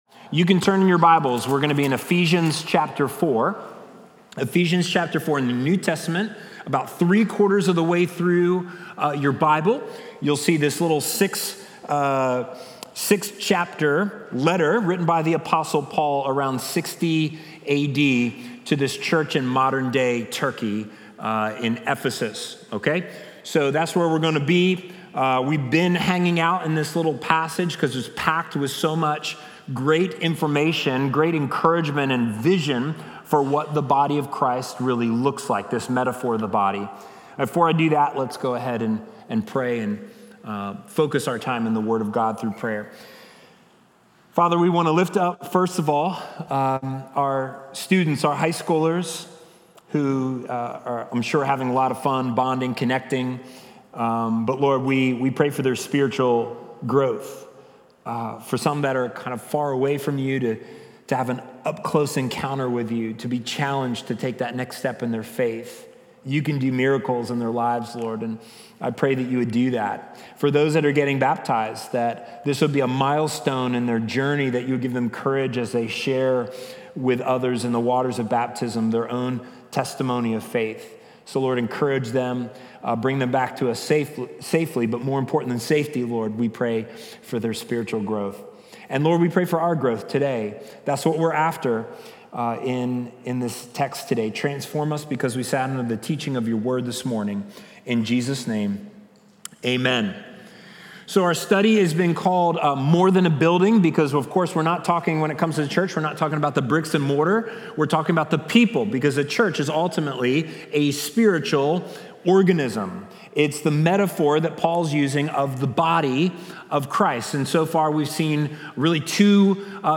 Watch previously recorded Sunday sermons.
Communion is part of the service.